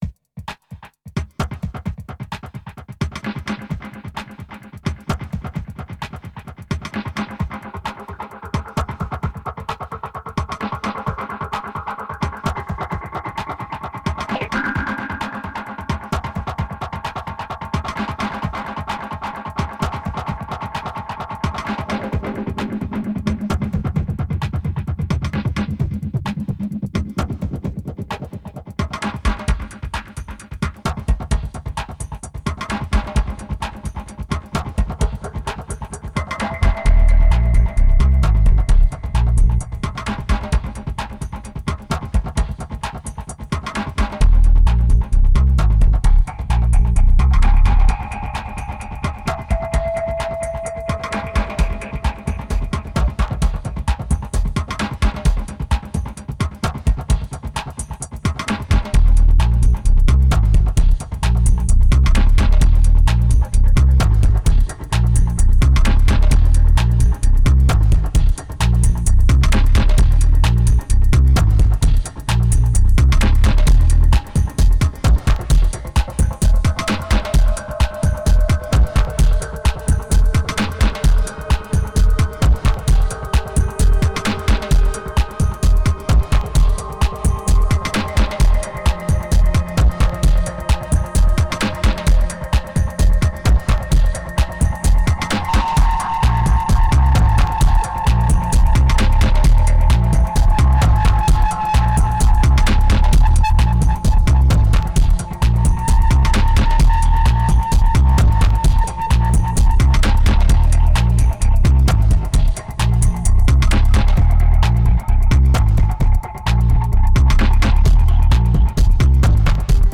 1943📈 - -29%🤔 - 131BPM🔊 - 2010-09-05📅 - -255🌟